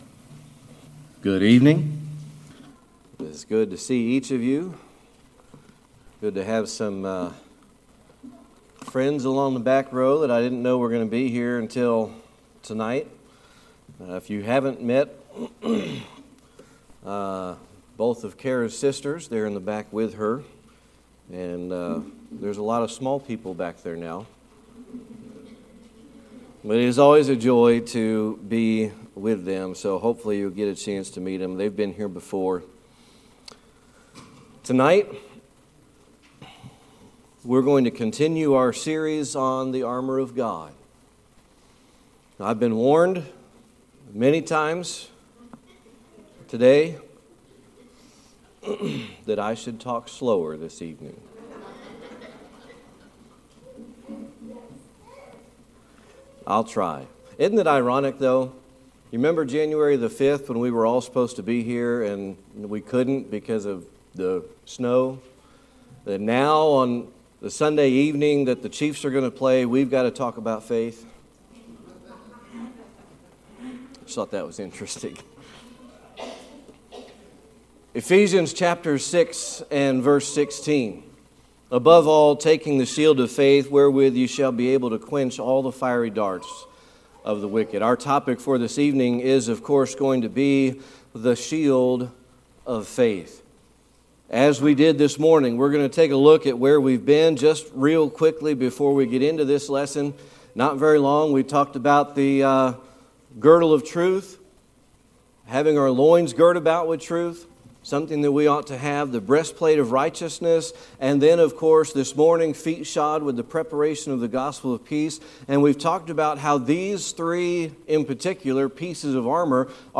Explore the Armor of God in this powerful sermon about the Shield of Faith. Discover the significance of faith that causes one to act towards their own safety.
Ephesians 6:16 Service Type: Sunday Evening Worship Tonight